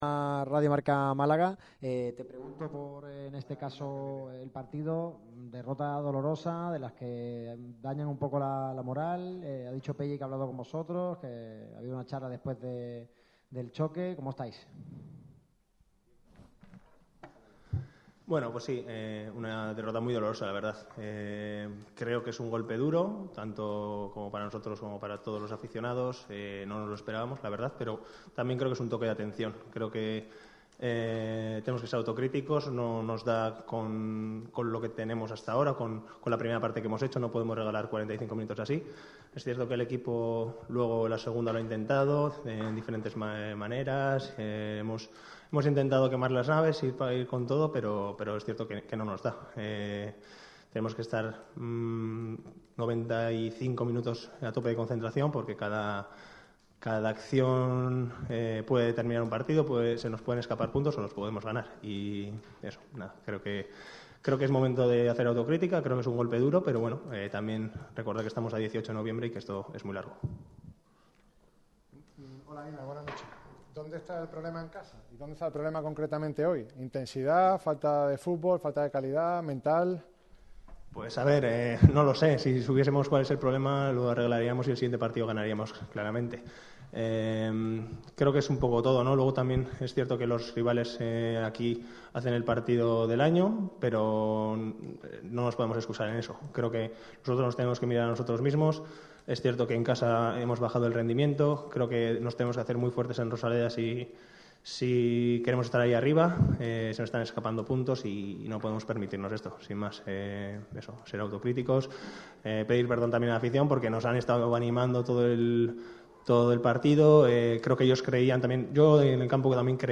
El central vasco del Málaga CF ha comparecido ante los medios tras la derrota de los boquerones en La Rosaleda ante el Alcoyano (1-2).